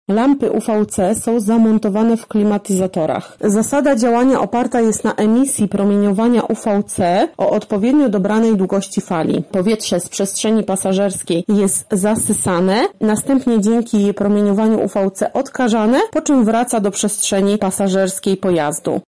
O tym, jak działają lampy mówi